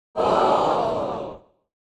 successCrowd.wav